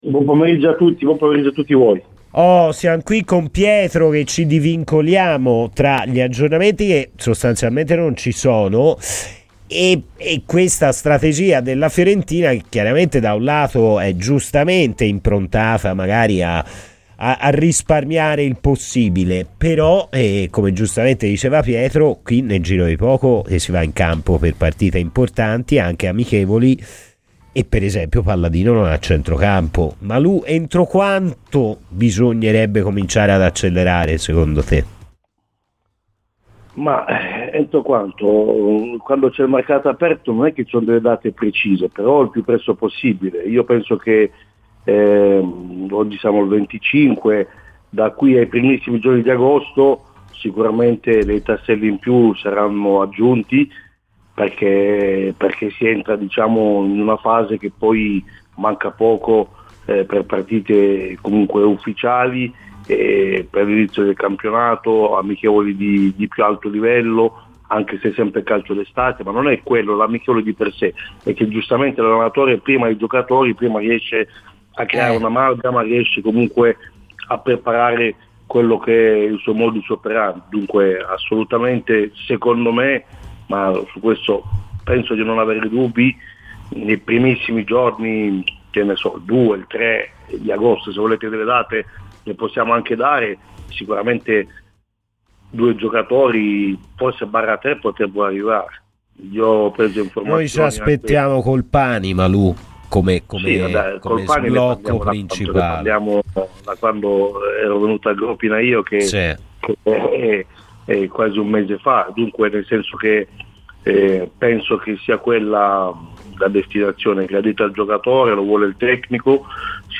direttore sportivo, è intervenuto ai microfoni di Radio FirenzeViola durante la trasmissione "Palla al centro"